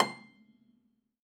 53p-pno18-C4.wav